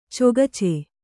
♪ cogace